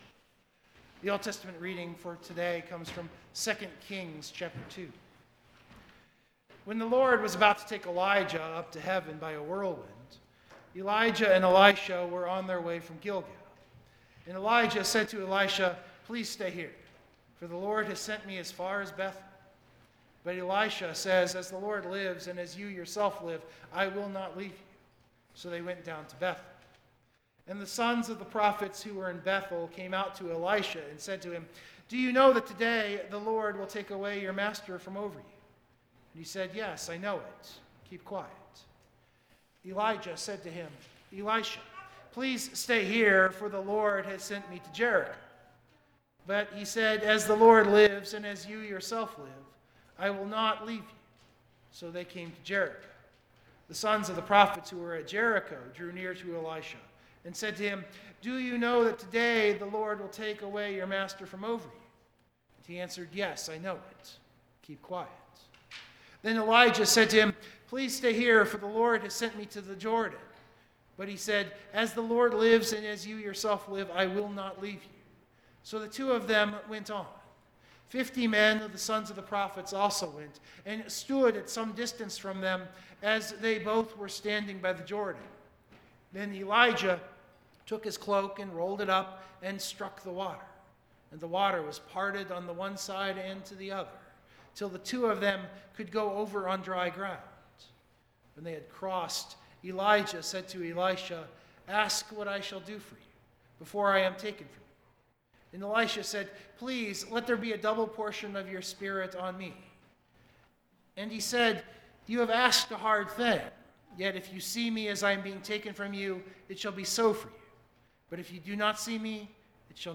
060224-Sermon Download Biblical Text